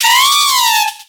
Cri de Lianaja dans Pokémon X et Y.